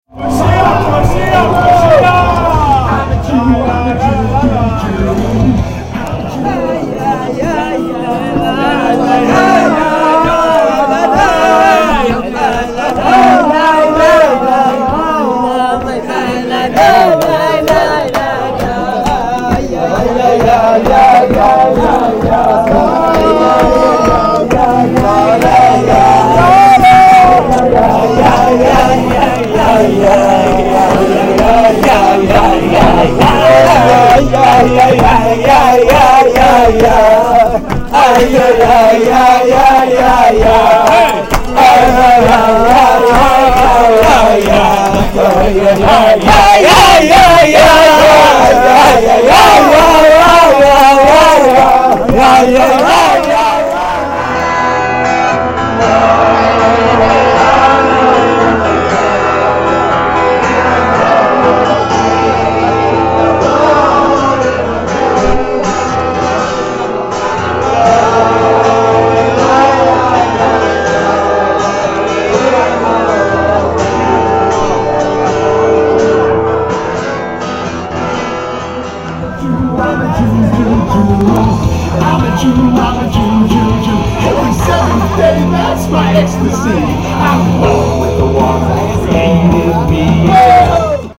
The track you are listening to includes three clips: I'm a Jew (beginning and reprise), a wildly kitschy recording that someone threw on the deck, a Chasidic nigun some of us dance to in a sweaty cricle, and an electric version of a Shlomo tune.